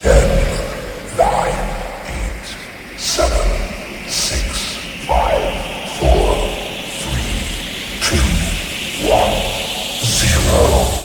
timer.ogg